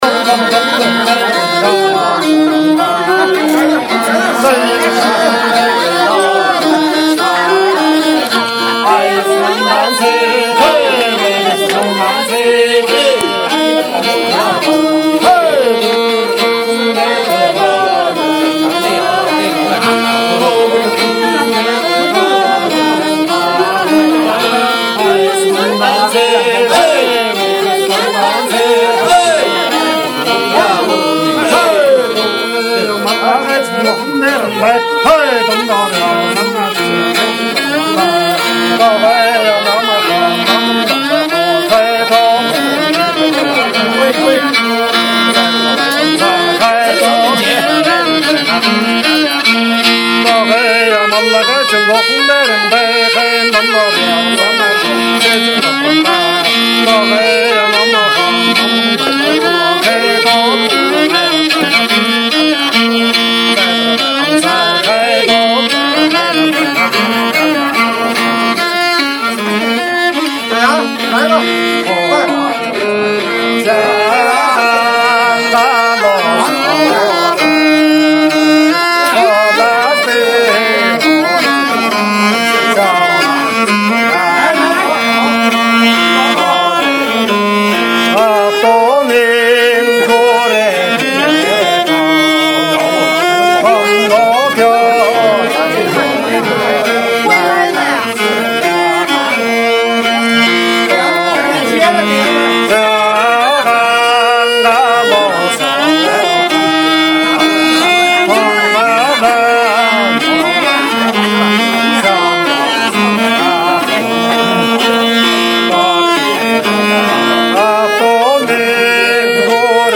请问哪位高人知道这两首草原歌曲名？歌曲附后